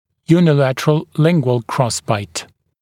[juːnɪ’lætərəl ‘lɪŋgwəl ‘krɔsbaɪt][йу:ни’лэтэрэл ‘лингуэл ‘кросбайт]односторонний лингвальный перекрестный прикус